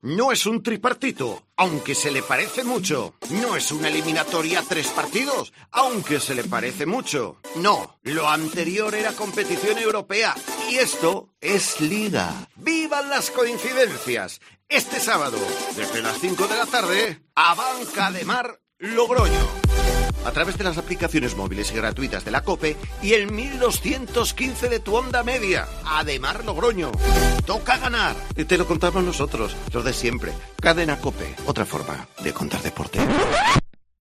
Escucha la cuña promocional del partido Abanca Ademar-Logroño el día 02-10-21 a las 17:00 h en el 1.215 OM